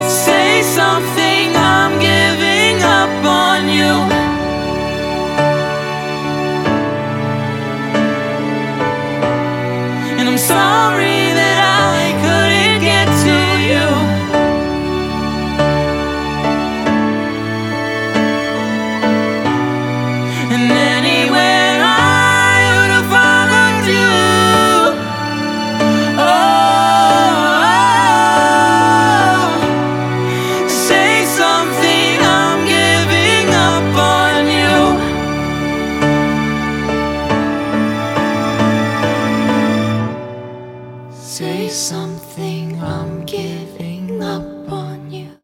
• Качество: 320, Stereo
поп
мужской вокал
женский вокал
грустные
пианино